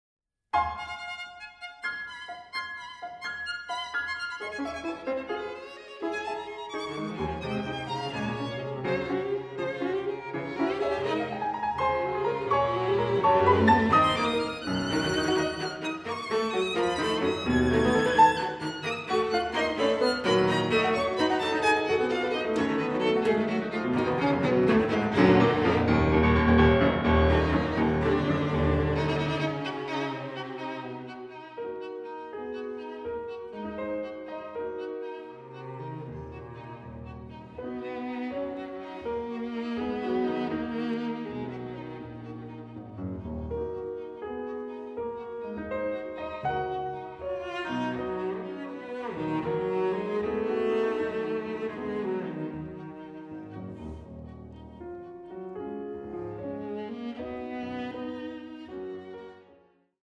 II. Presto e leggiero